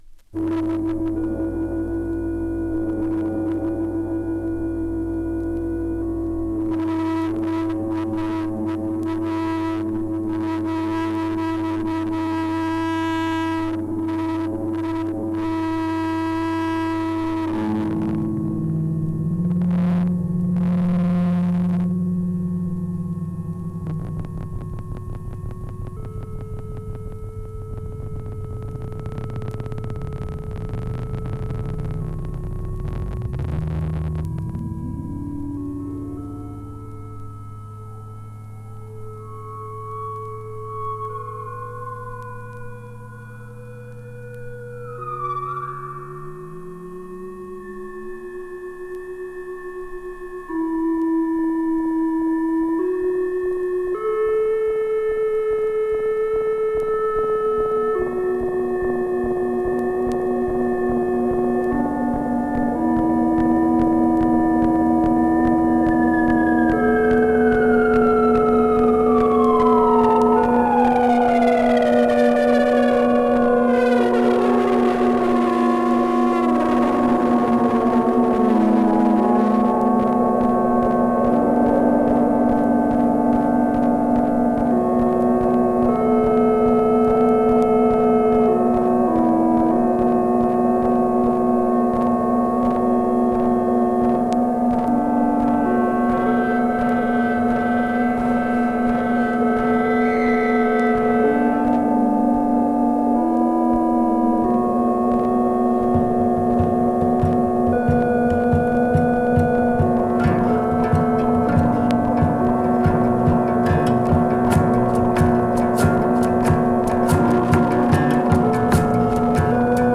やけに漢臭さを感じさせるアングラなヘヴィー・サイケデリック・サウンド!